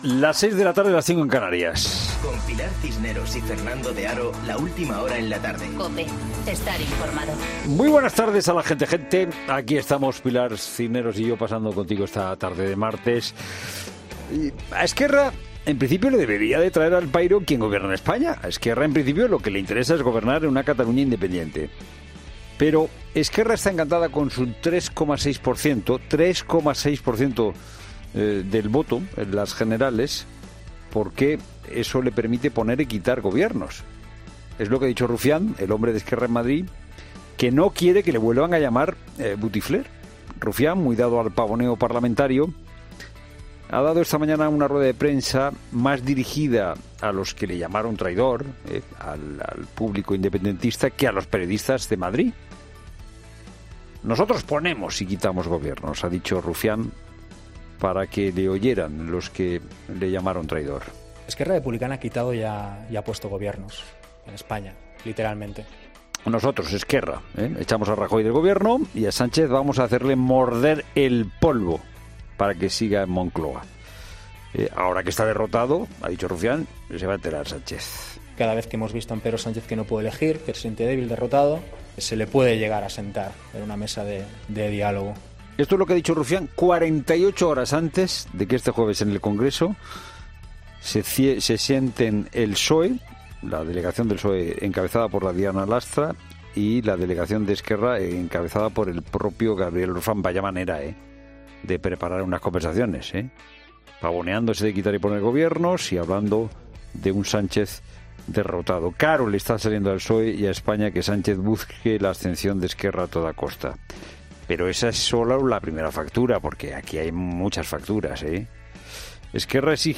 Boletín de noticias COPE del 26 de noviembre a las 18:00 horas